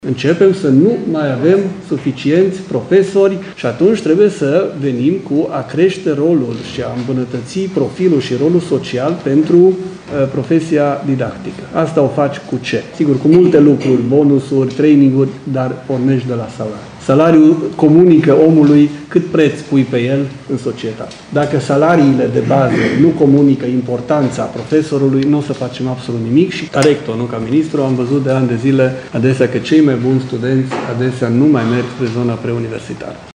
Ministrul Educației, Daniel David: „Salariile comunică omului cât preț pui pe el în societate”